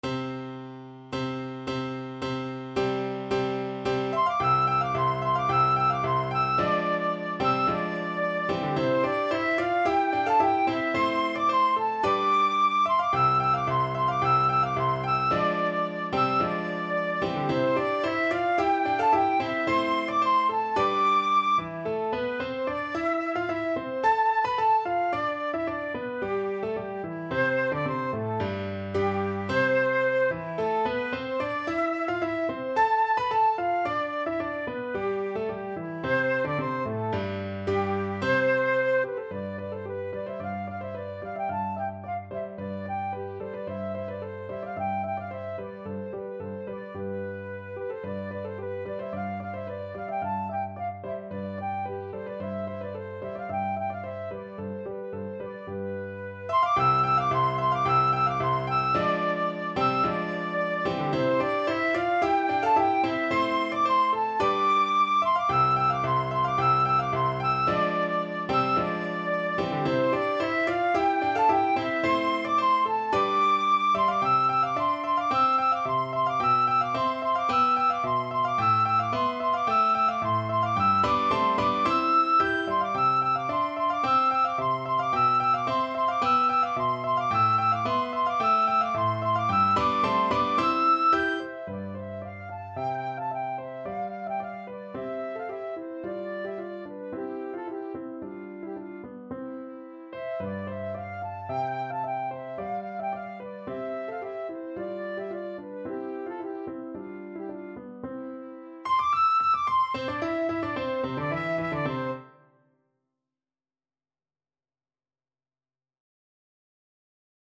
Flute version
= 110 Allegro di molto (View more music marked Allegro)
2/2 (View more 2/2 Music)
Classical (View more Classical Flute Music)